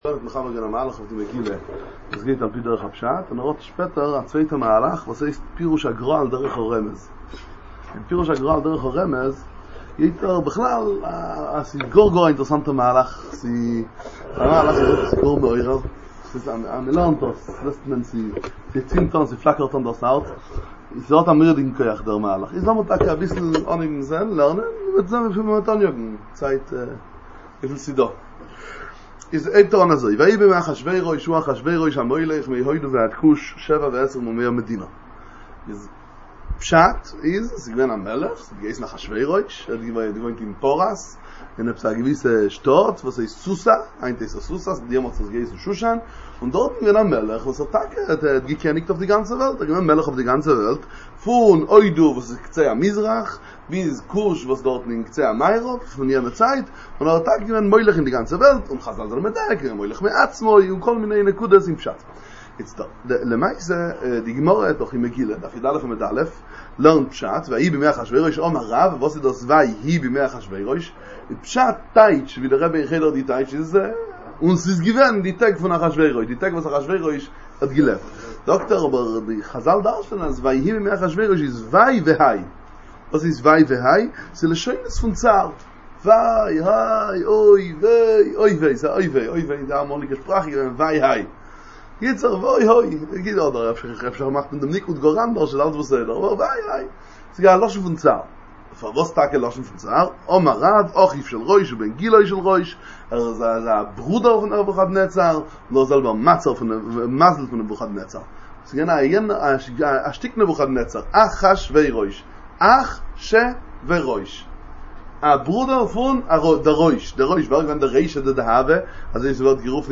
שיעור על מגילת אסתר לפי ביאור הגר"א על דרך הרמז, באידיש